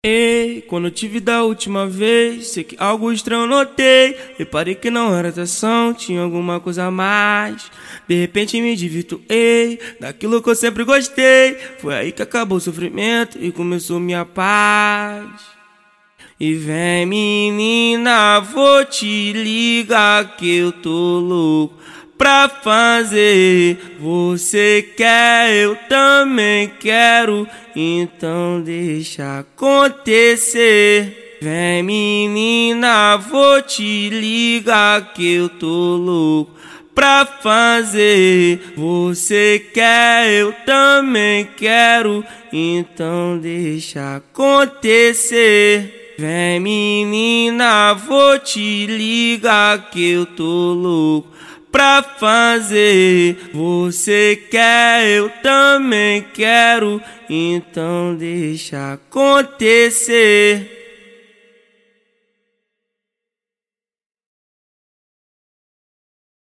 Acapellas de Funk